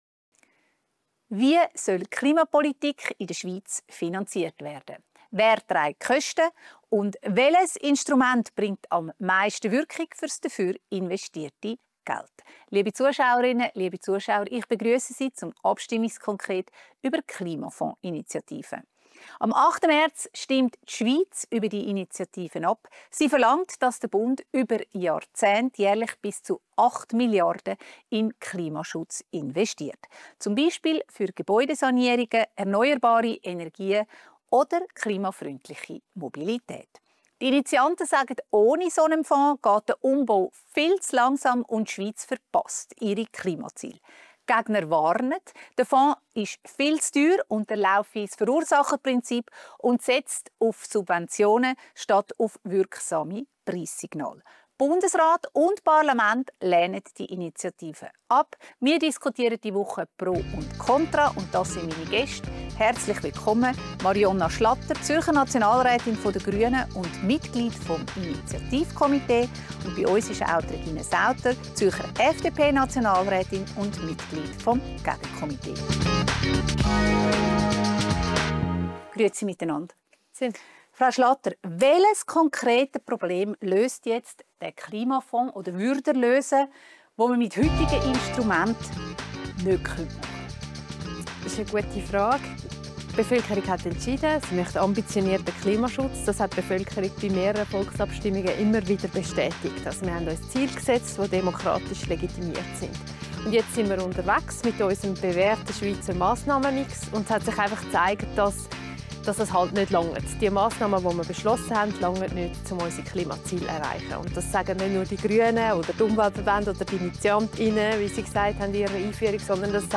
diskutiert im Abstimmungskonkret über die Klimafonds-Initiative vom 8. März mit den beiden Zürcher Nationalrätinnen Marionna Schlatter, Grüne und Regine Sauter, FDP.